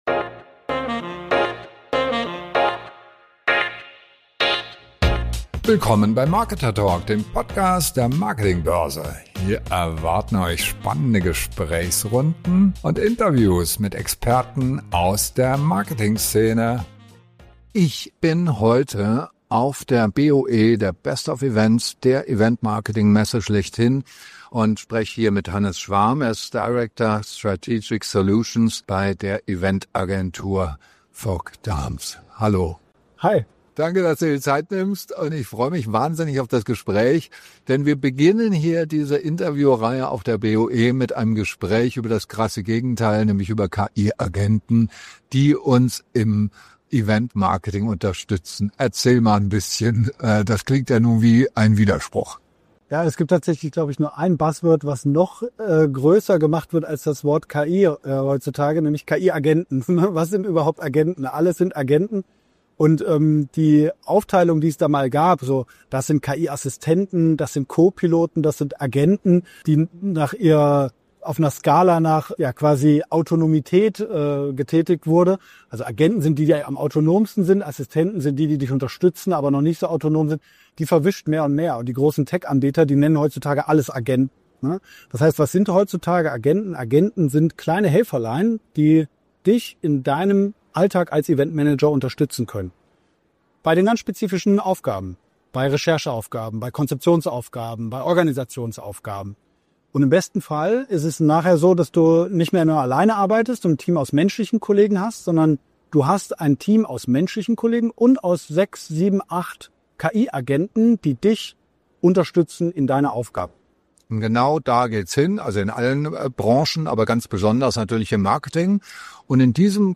KI-Agenten unterstützen Event-Manager dabei, ihre Planungszeit um 40 bis 60 Prozent zu reduzieren. Im Interview erfährst du, wie du sie konkret in deine bestehenden Tools integrierst und deine Zielgruppe mithilfe von Digital Doppelgängern vor dem Event validierst.